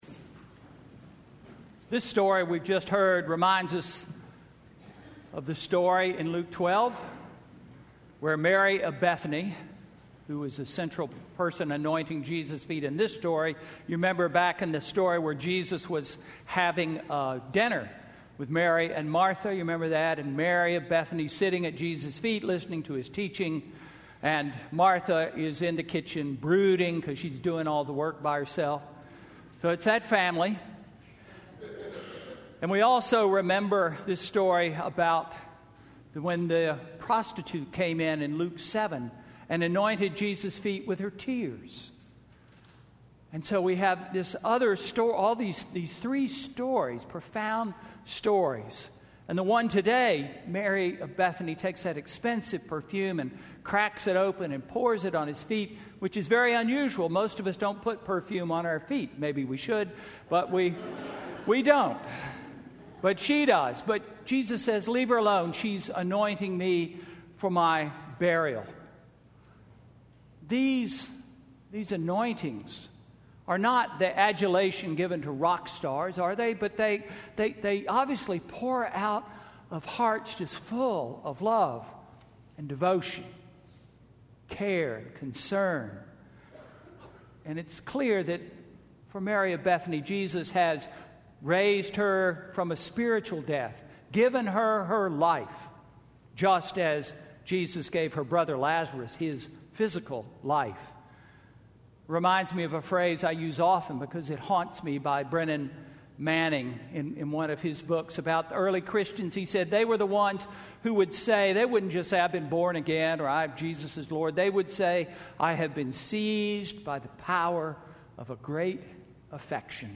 Sermon–March 13, 2016